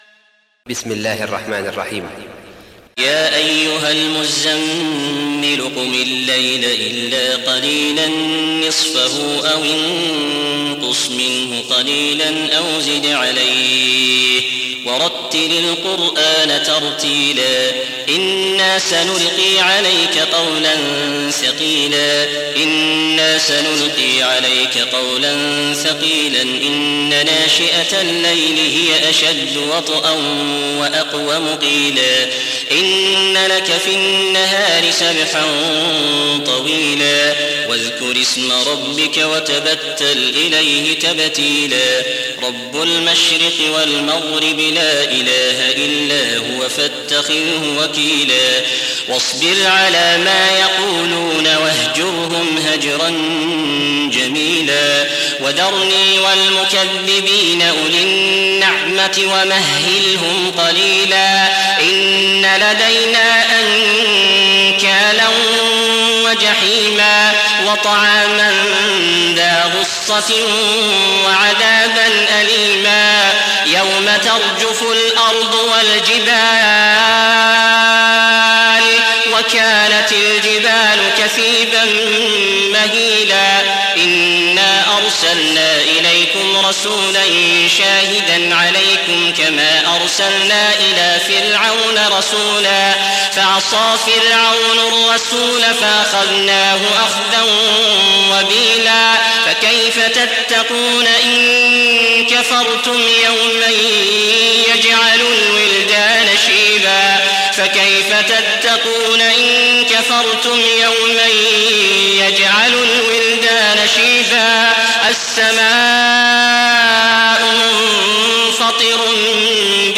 Riwayat Hafs dari Asim